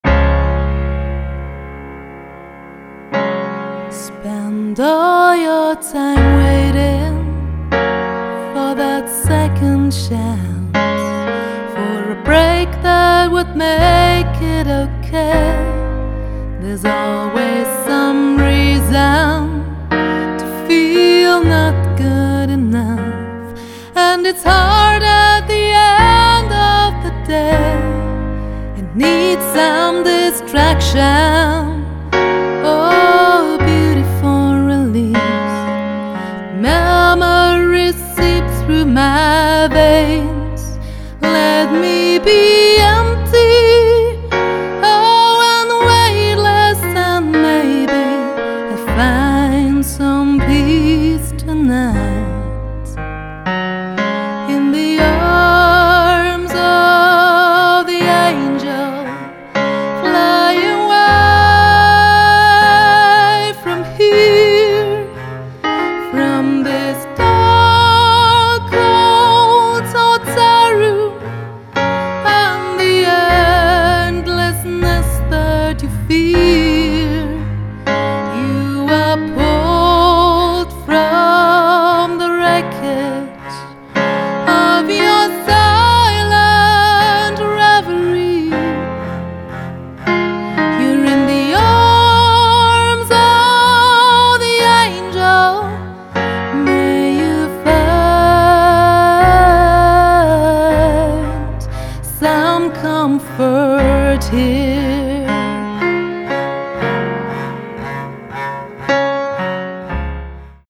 aus den Sparten Pop, Musical und Deutsch-Chanson